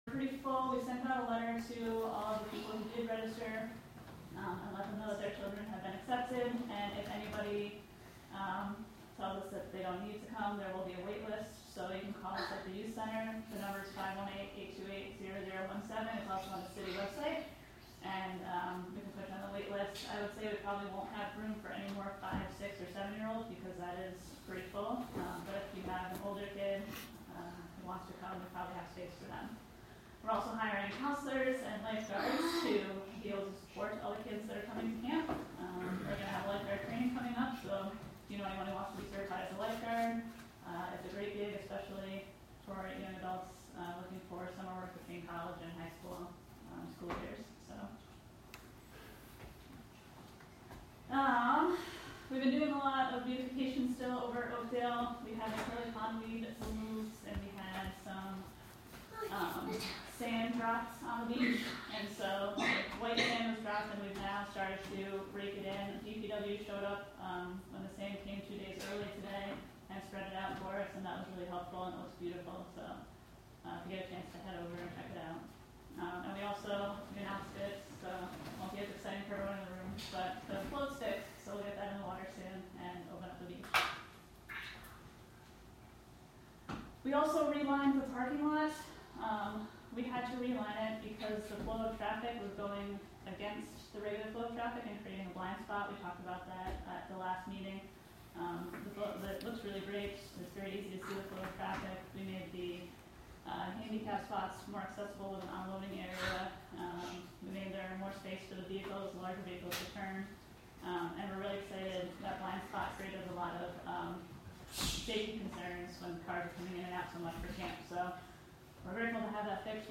Live from the City of Hudson: Hudson Common Council (Audio)